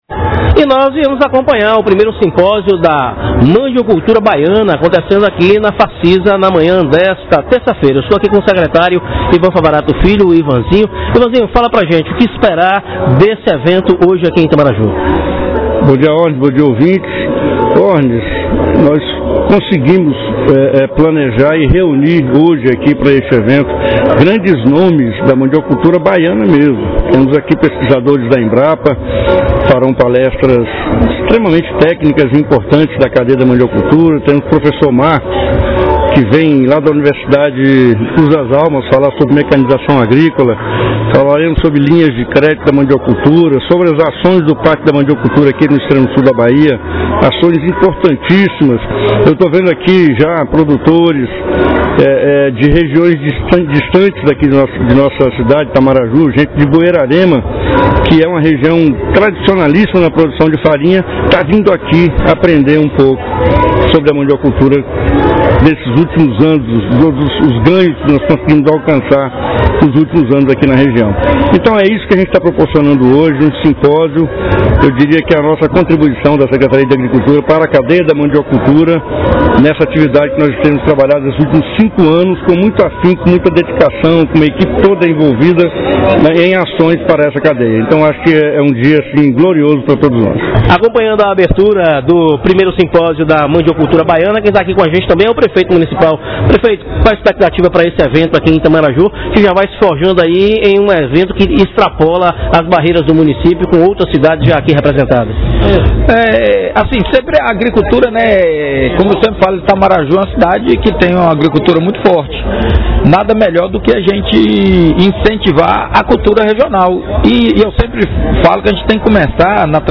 O prefeito Marcelo Angênica e o secretário de agricultura Ivan Favarato Filho.